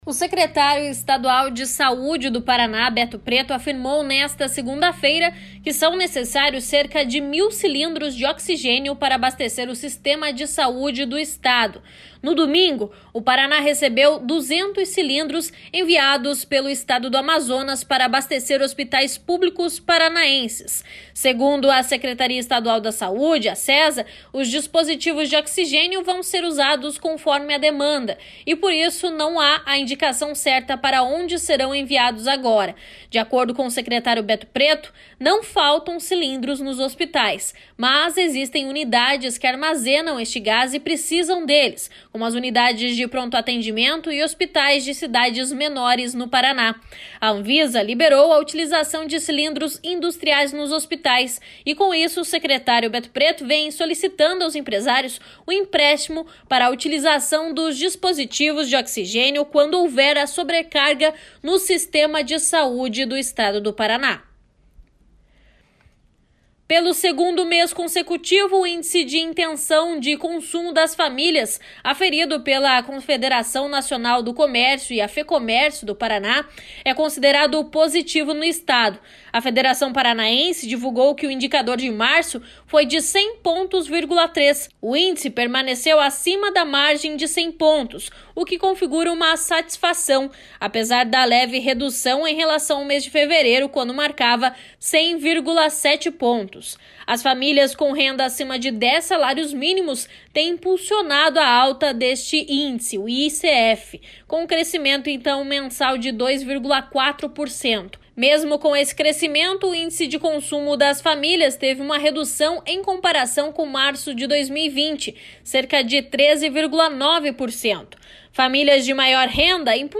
Giro de Notícias da manhã SEM TRILHA